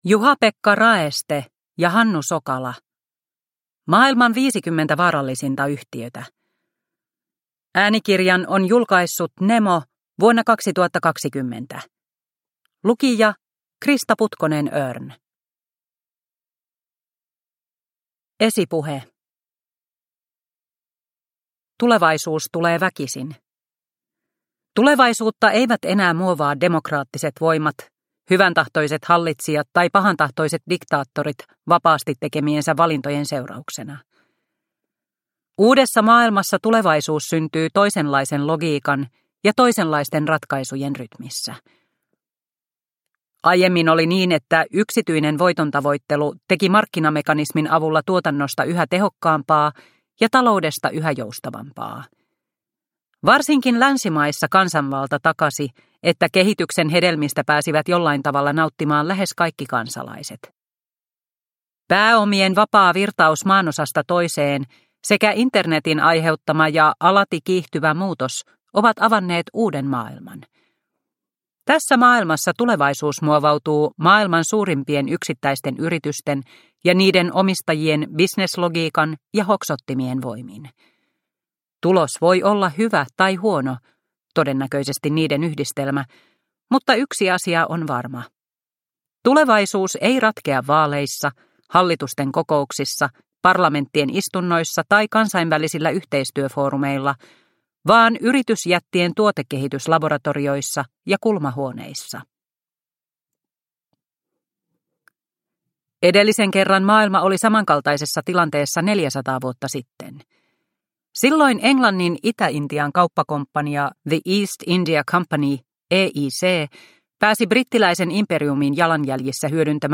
Maailman 50 vaarallisinta yhtiötä – Ljudbok – Laddas ner